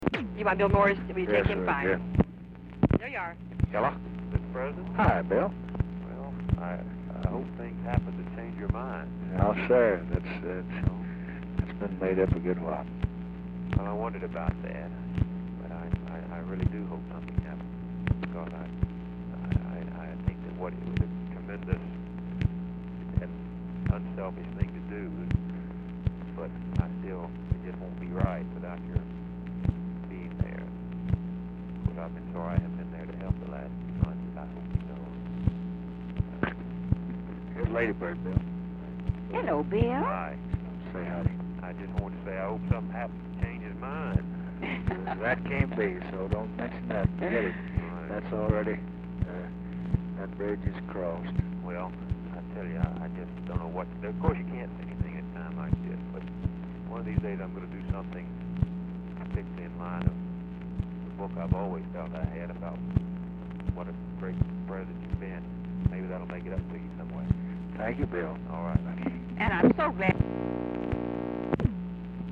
Telephone conversation # 12855, sound recording, LBJ and BILL MOYERS, 3/31/1968, 10:27PM
RECORDING ENDS BEFORE CONVERSATION IS OVER
Format Dictation belt
Location Of Speaker 1 Mansion, White House, Washington, DC